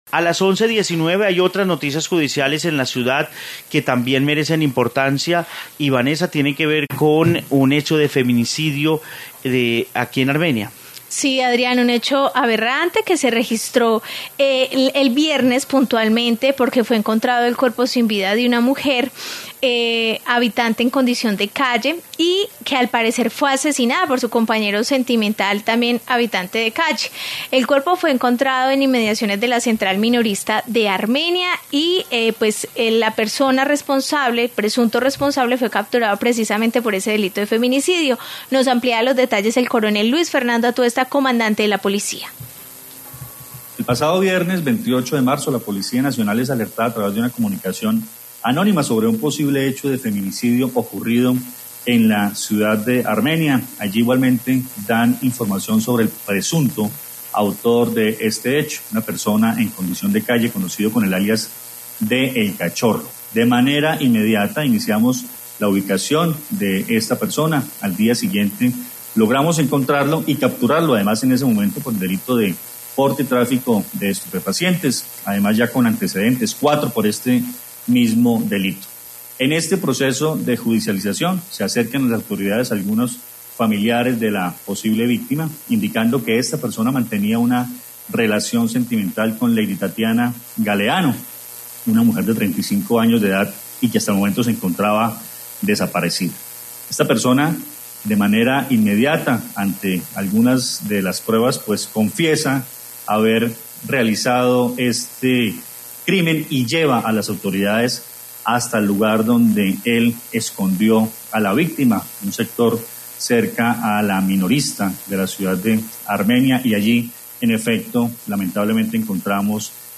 Informe caso de mujer trans en Armenia